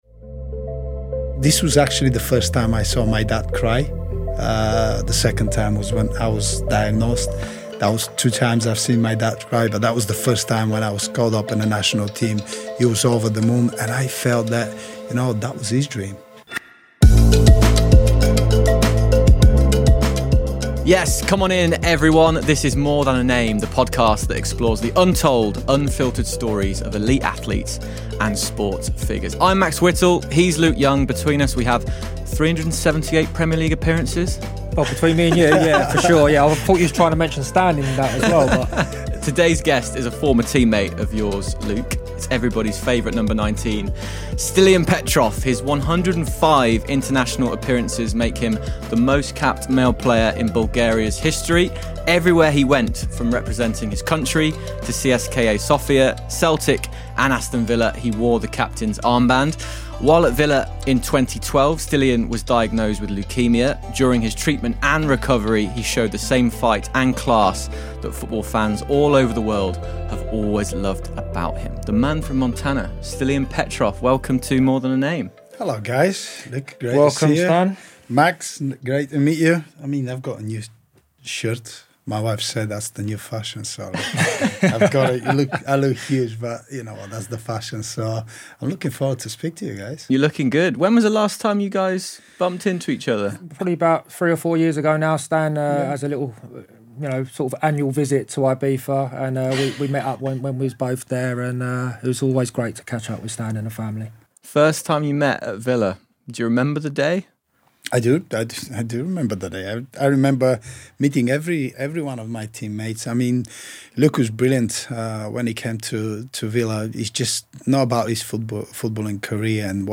In this episode, we sit down for a raw and honest conversation about what was really going on behind the image.